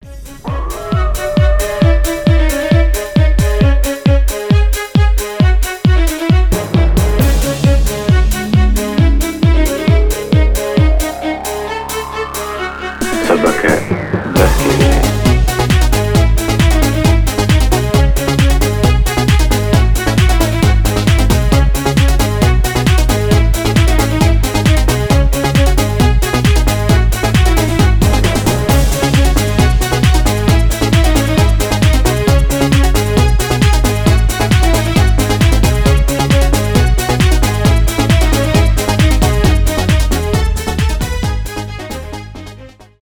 euro house
скрипка , инструментальные , евродэнс
танцевальные